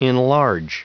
Prononciation du mot enlarge en anglais (fichier audio)
Prononciation du mot : enlarge